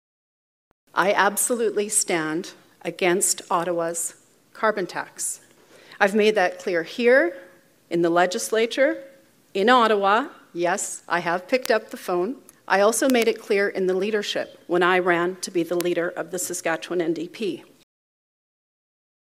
NDP leader Carla Beck spoke at the Saskatchewan Association of Rural Municipalities (SARM) annual convention in Regina on Wednesday afternoon and talked about the need for urban and rural areas to work together.